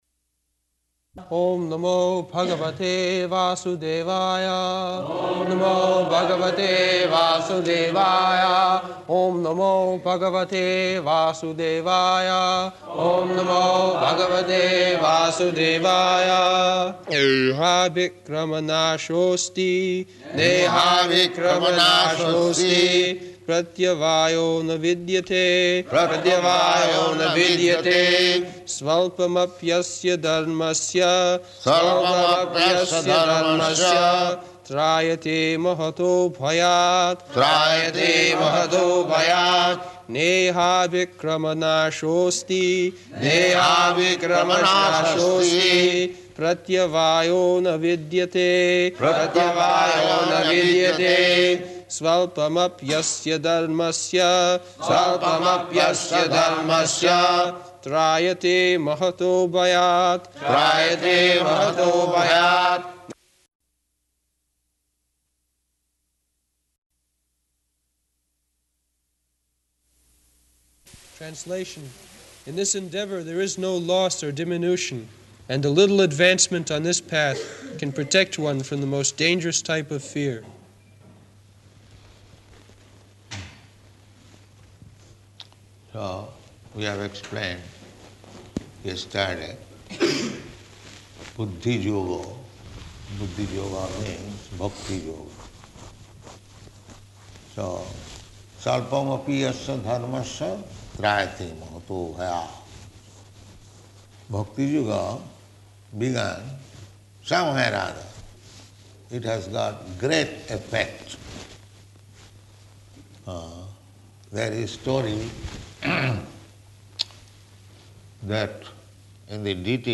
September 13th 1973 Location: London Audio file
[Prabhupāda and devotees repeat]